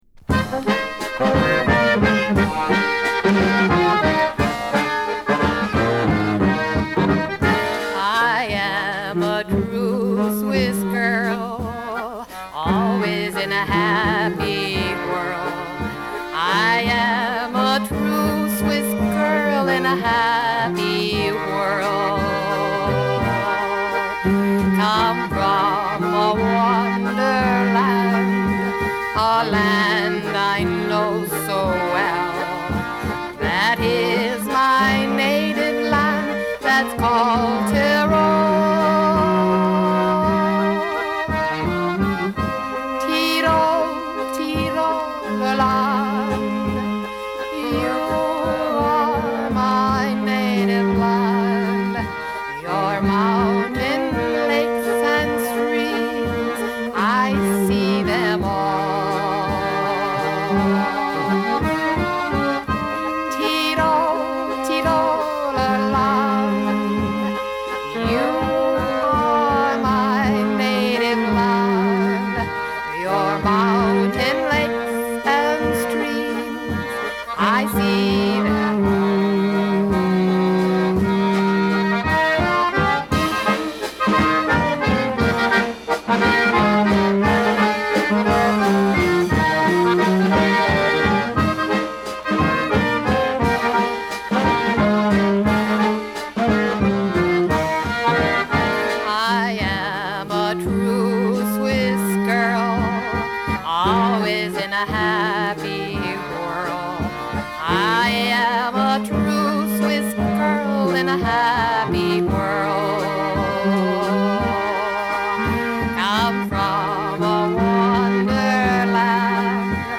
ミルウォーキー出身で地元周辺のライヴほかラジオ、テレビにも出演したアコーディオン奏者。
素朴さで未加工なサウンドにハッとさせられる。